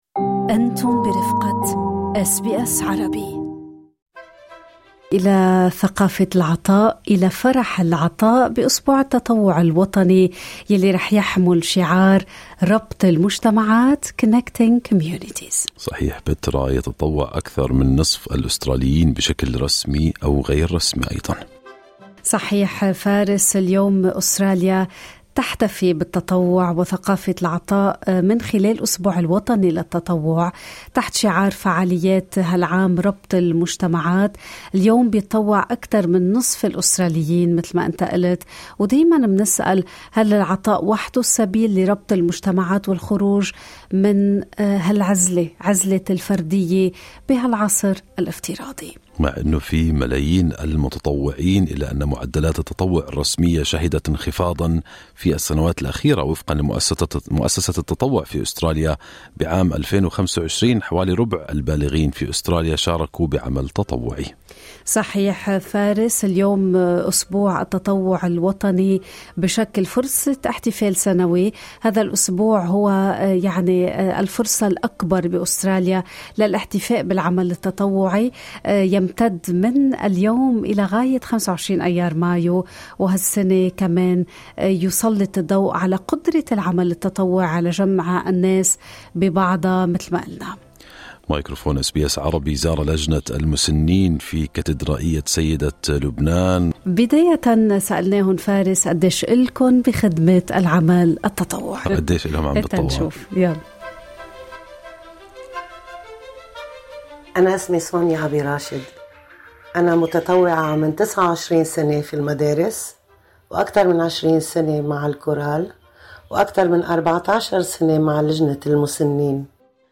Our Lady of Lebanon co-cathedral Seniors' committee share their moving testimonial on volunteering & serving the elderly.